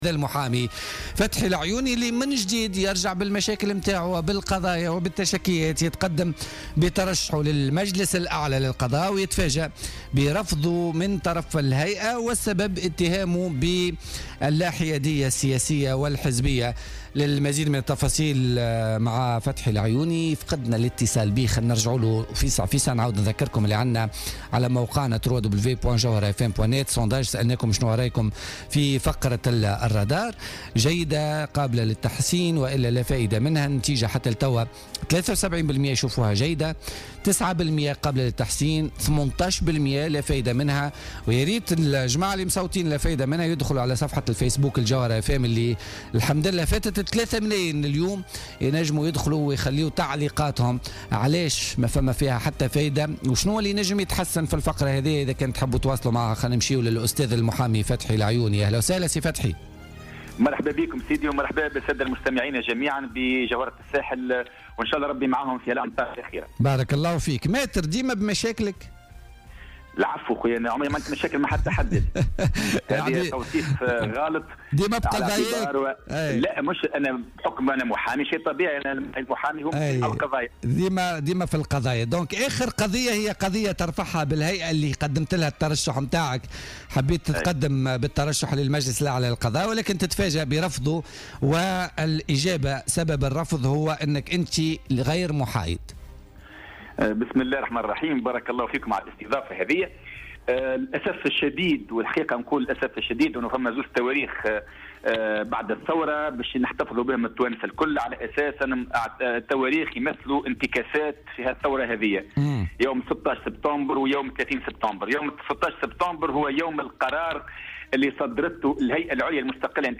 في مداخلة له على الجوهرة 'اف ام